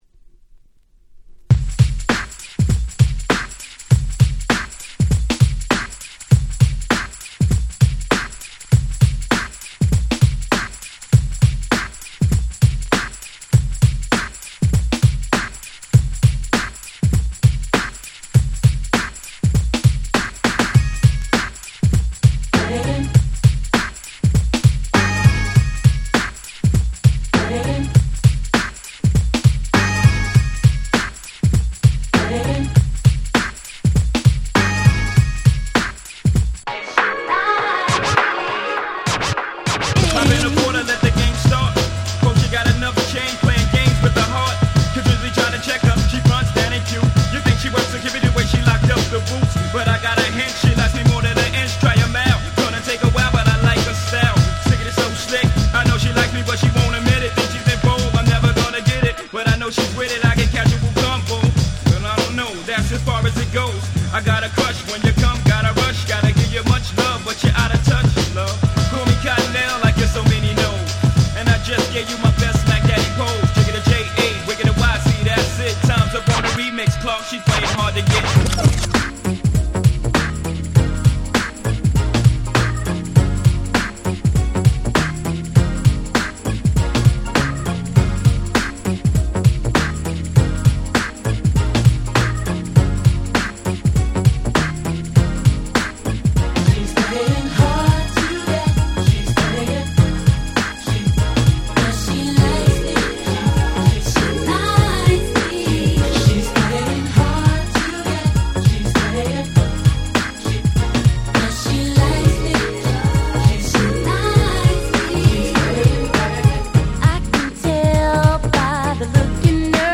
White Press Only Remix / Re-Edit !!
ニュージャックスィング NJS ハネ系 キャッチー系